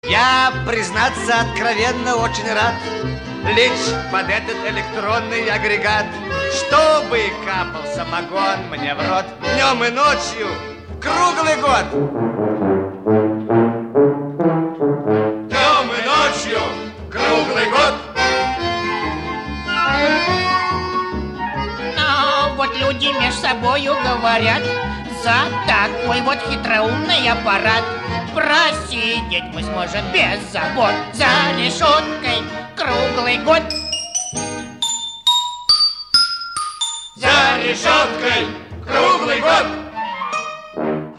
• Качество: 128, Stereo
мужской голос
веселые
пьяные
труба